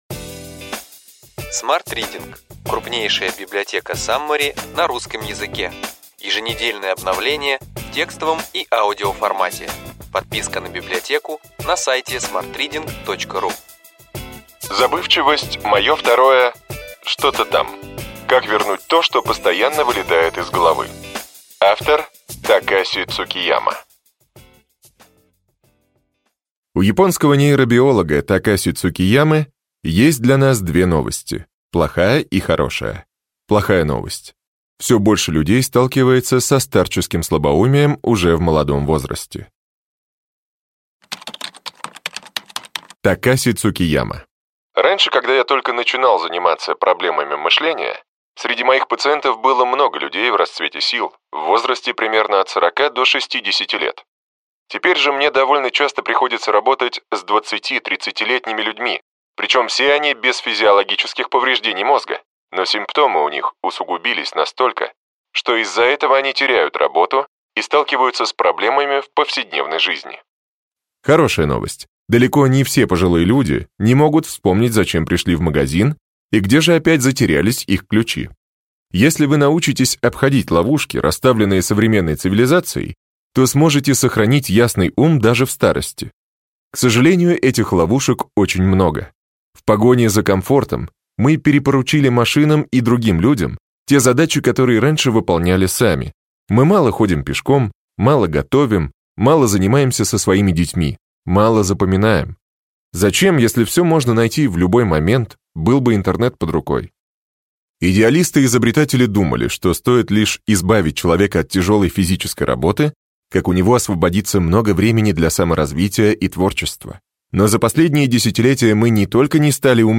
Аудиокнига Ключевые идеи книги: Забывчивость – мое второе… что-то там. Как вернуть то, что постоянно вылетает из головы.